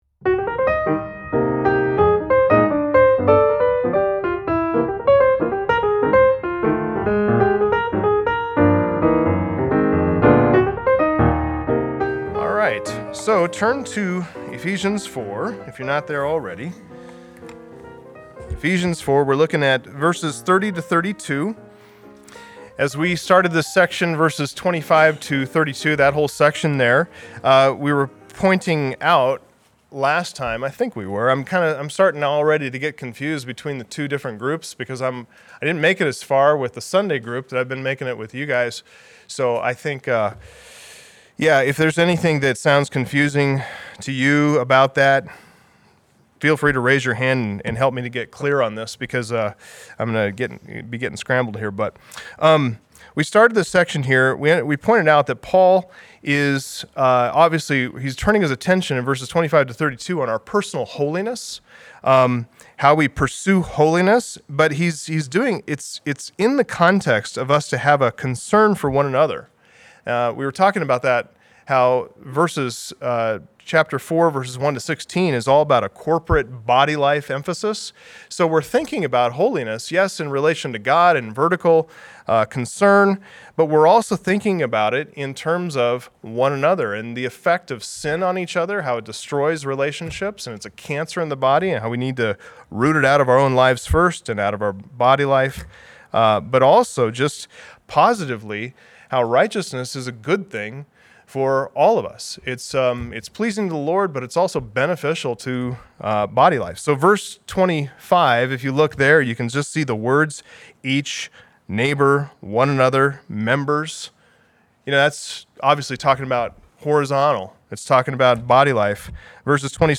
Biblical Study